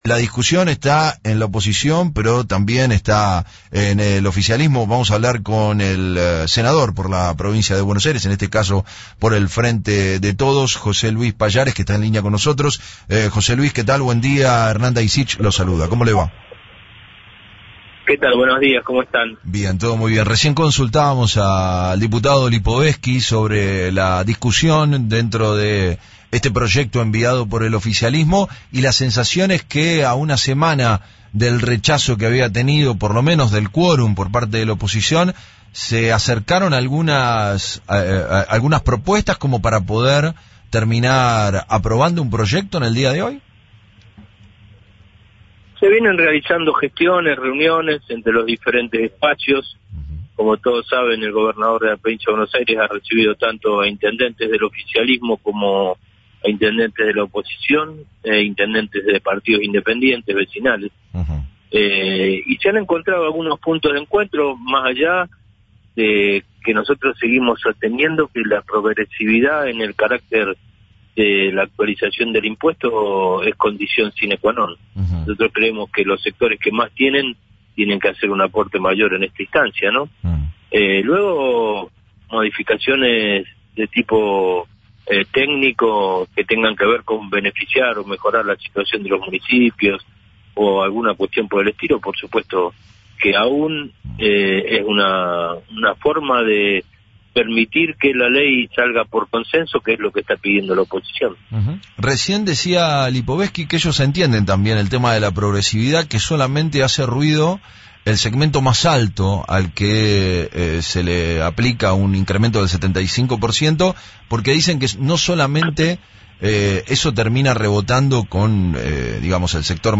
En declaraciones al mismo medio, Pallares se mostró en sintonía con Lipovetzky al hacer hincapié en la búsqueda de consenso. De hecho, indicó que hubo gestiones entre el gobernador y los intendentes oficialistas y opositores, incluyendo a los de partidos independientes.